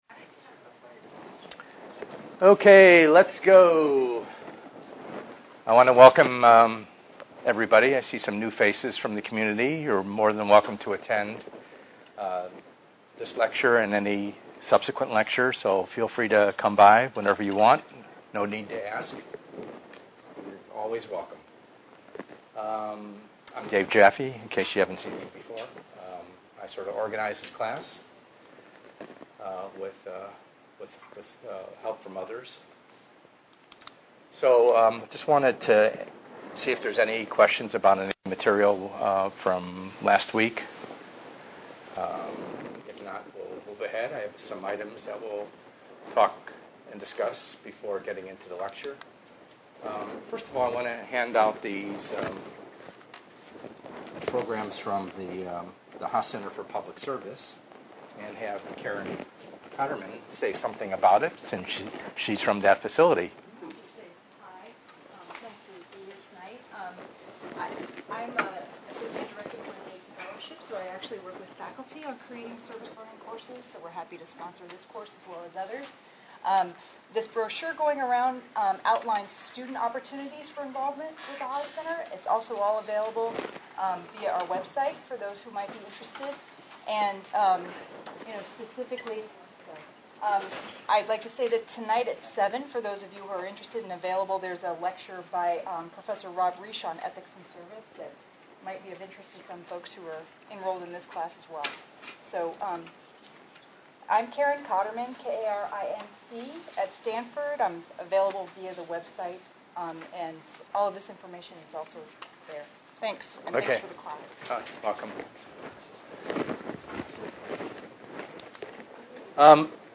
ENGR110/210: Perspectives in Assistive Technology - Lecture 6a